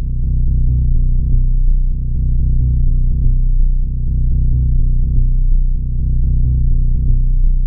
• Tech House Bass Rhythm Sustained Melody.wav
Loudest frequency 53 Hz
Tech_House_Bass_Rhythm_Sustained_Melody_g8q.wav